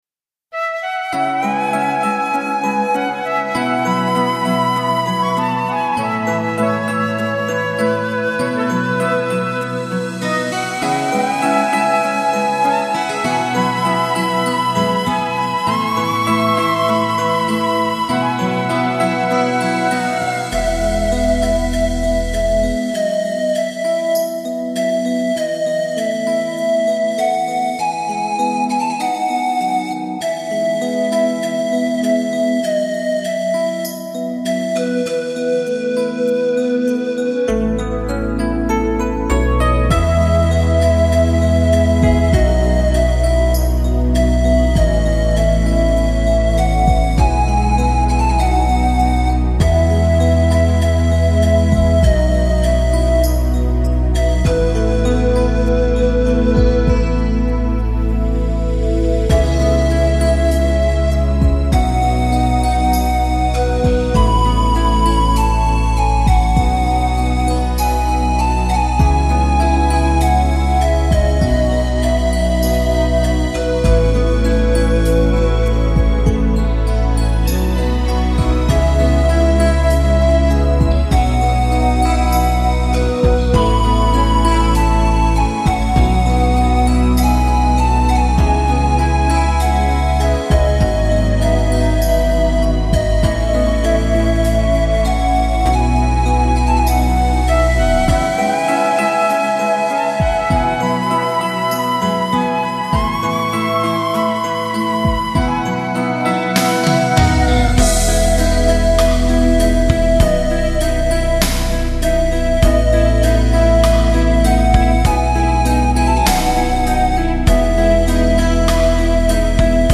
רעיונות לשיר במנגינה דרמטית